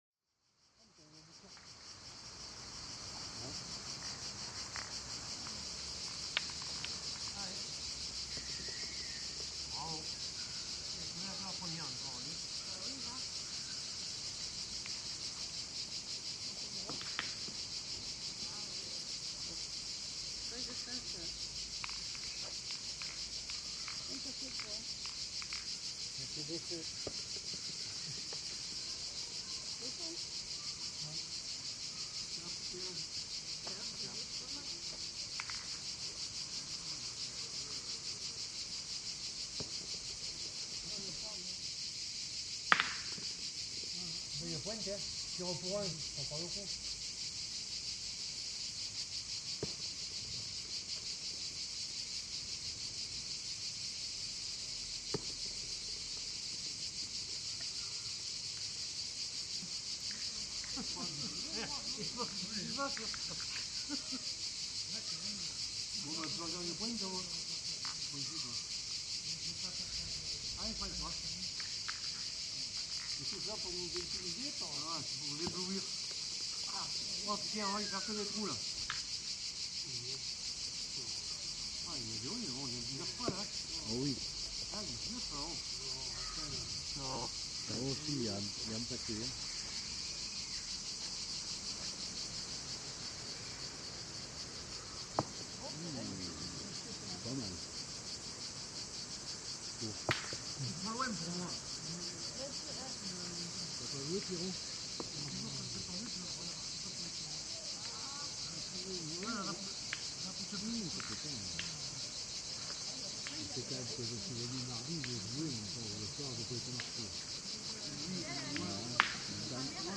This is a recording of a part of a traditional south french metal ball game. On the background there are many typical cicada that immediately give an ambience.
All in all they invited me to participate, they explained the rules, of course in their local accent!